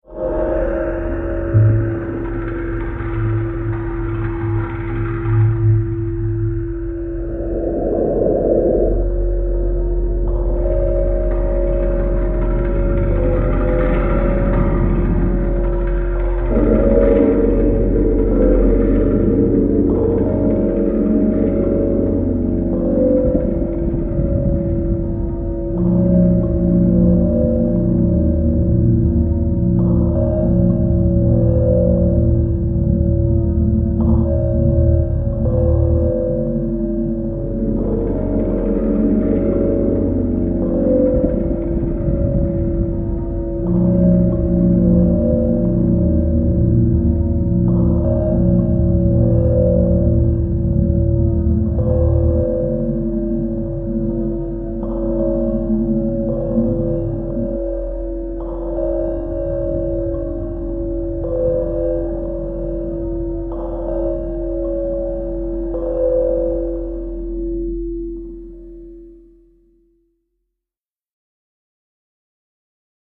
Planet Cave Ambience Cave, Planet, Sci-fi